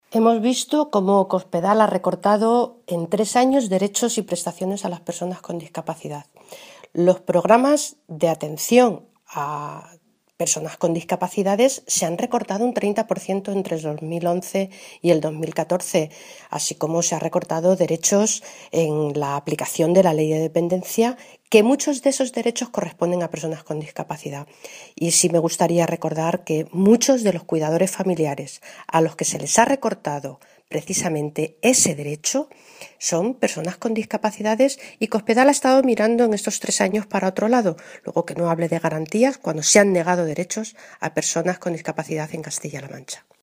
La diputada nacional del PSOE, Guadalupe Martín, ha señalado hoy que el proyecto de ley de garantía de los derechos de las personas con discapacidad, que ayer aprobó el Consejo de Gobierno de Castilla-La Mancha, “llega tarde, es electoralista y supone una falta de respeto a los discapacitados de nuestra región, que han venido sufriendo los recortes de Cospedal durante todos estos años”.
Cortes de audio de la rueda de prensa